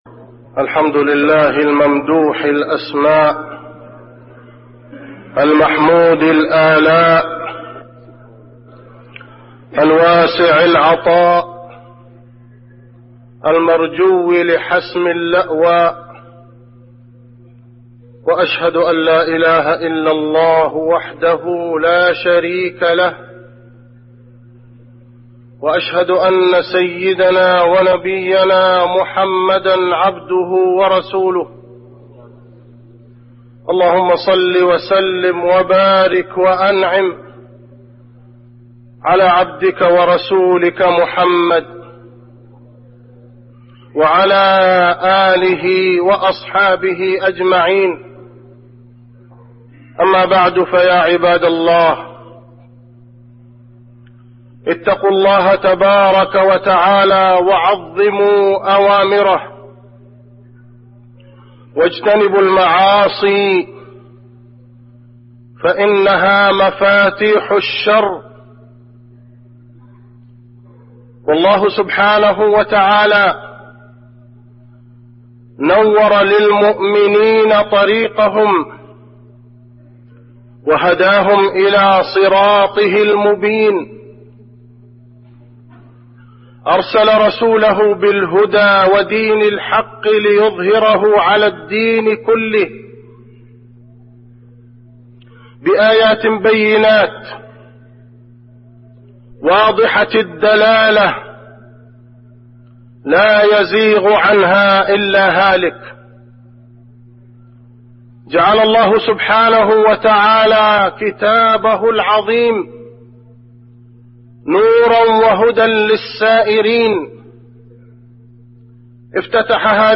خطبة الخسوف المدينة
المكان: المسجد النبوي